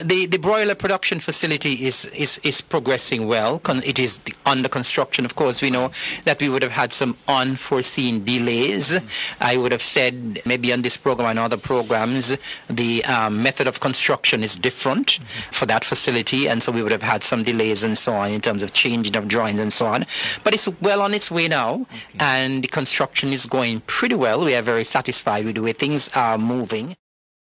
Nevis is making efforts to improve food resilience with continued work on a broiler production and processing facility, according to Agriculture Minister, Eric Evelyn. He spoke about the facility during his feature on the Government at Work Programme on April 9th, 2026: